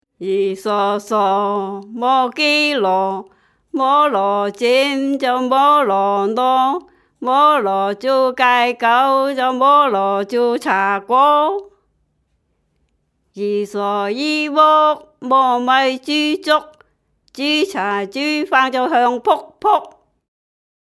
區內一群長者聚在一起，輪流唱著塵封了的古老歌謠，越唱越開懷，不覺間勾勒出昔日農鄉的浮世繪。